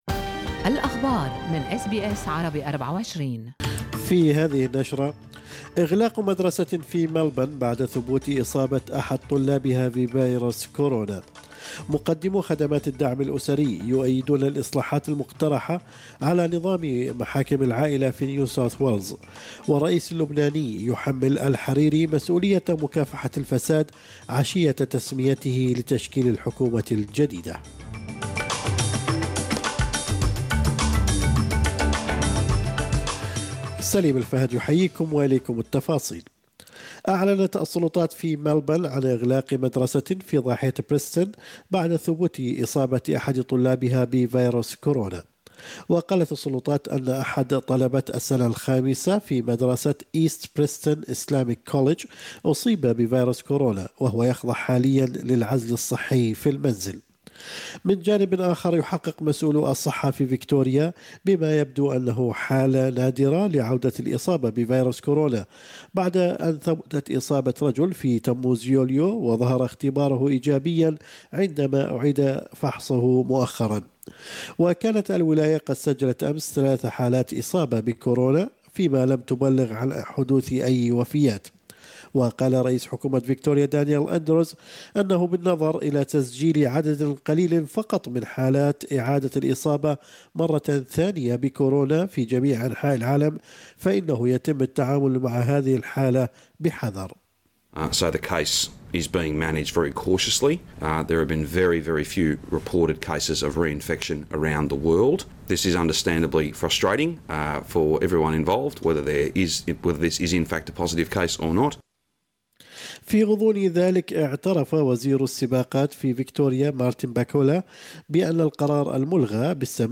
نشرة أخبار الصباح 22/10/2020